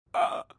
描述：Voz de personaje坟墓，没有领带palabras，独唱儿子sonidos de rabia。